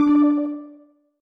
Techmino/media/effect/chiptune/ren_8.ogg at beff0c9d991e89c7ce3d02b5f99a879a052d4d3e
重做连击音效